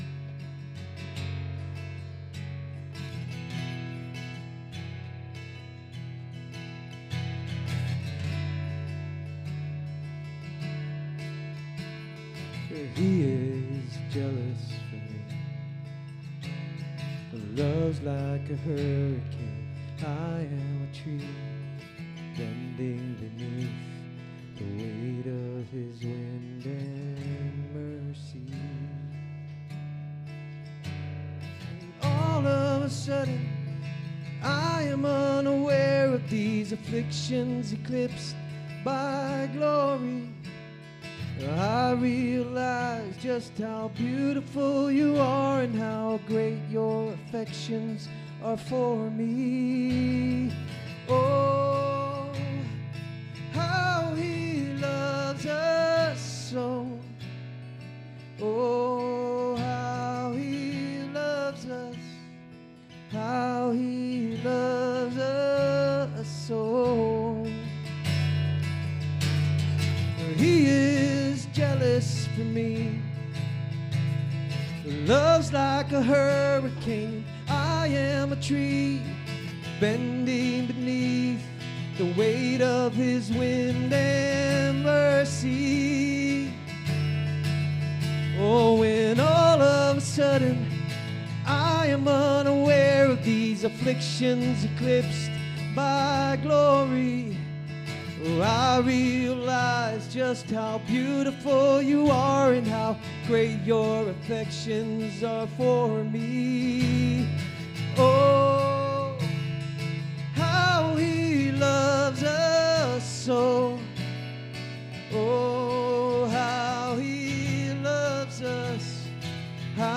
SERMON DESCRIPTION In this sermon, Jesus redefines greatness by revealing that true honor in God’s kingdom comes through humility, service, and childlike faith.